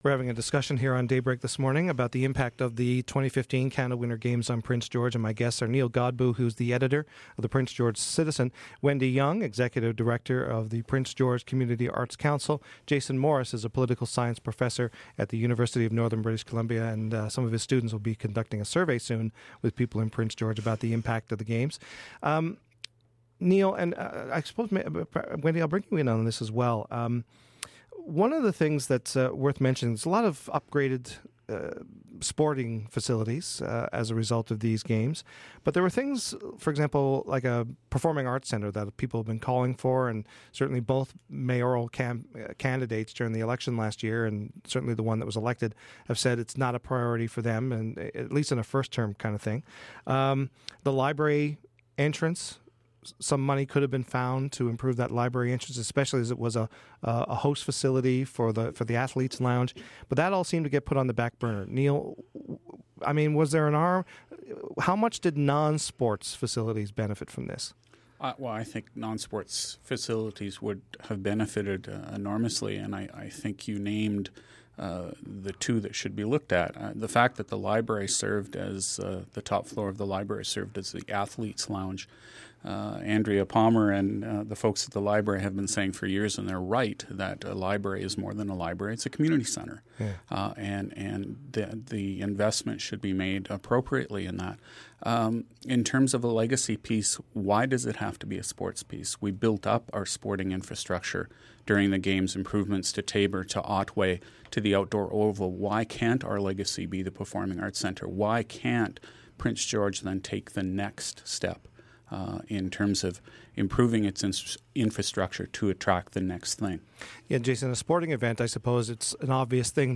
This is the second half of our conversation